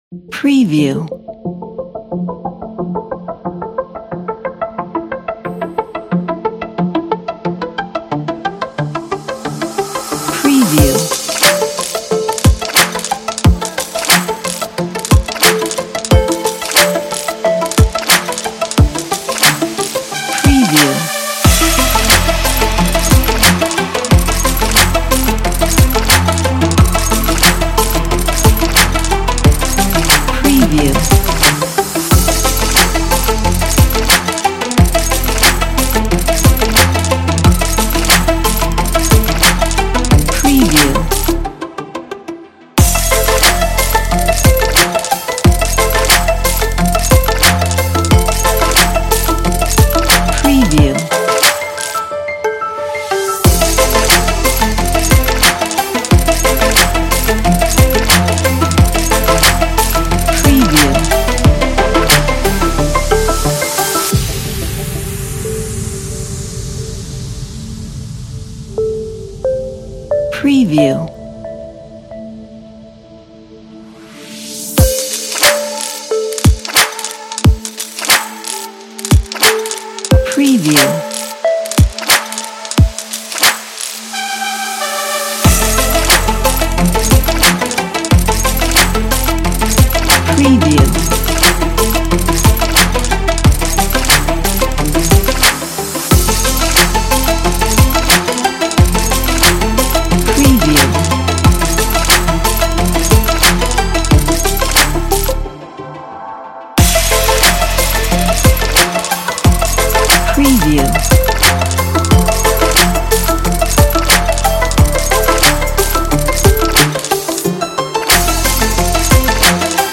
آهنگ بی کلام انگیزشی